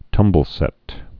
(tŭmbəl-sĕt)